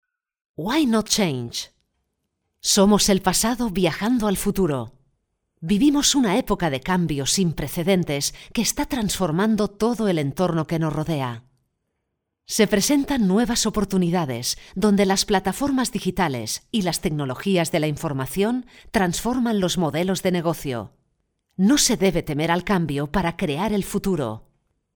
locutora de España. Locución de vídeo corporativo